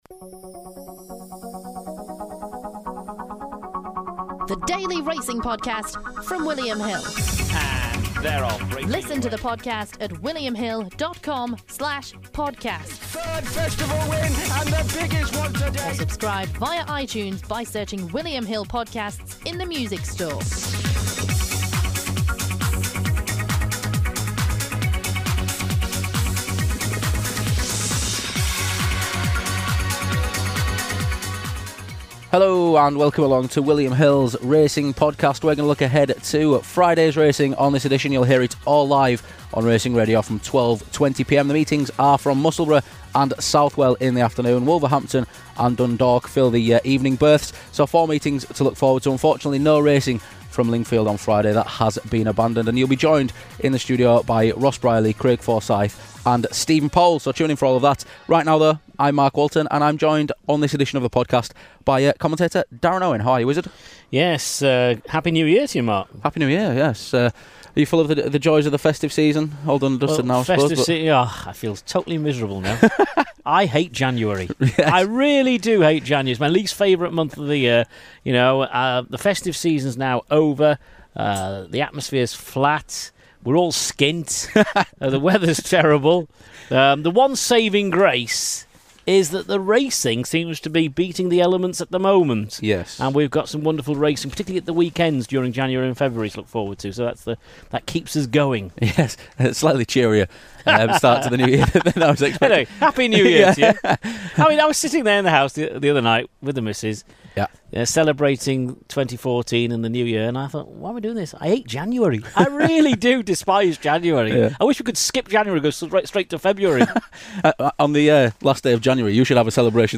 in the studio